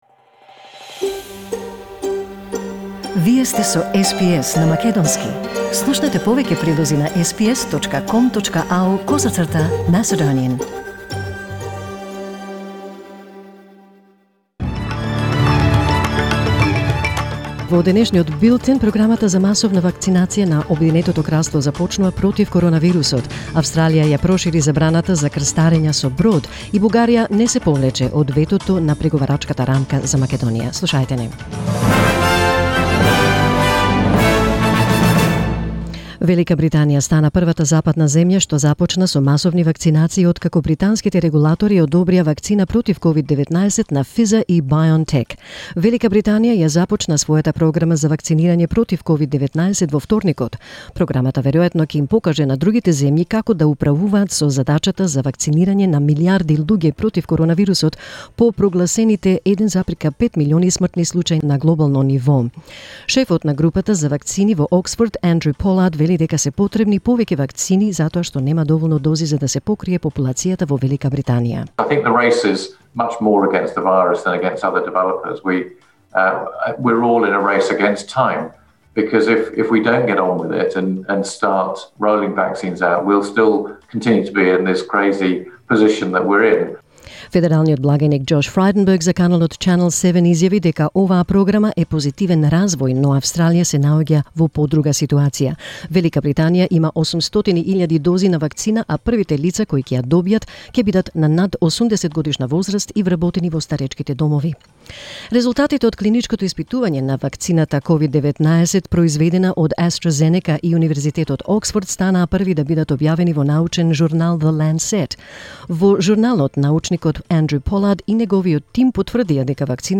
SBS News in Macedonian 9 December 2020